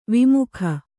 ♪ vimukha